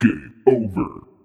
Game Over.wav